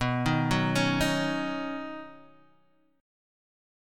Badd9 chord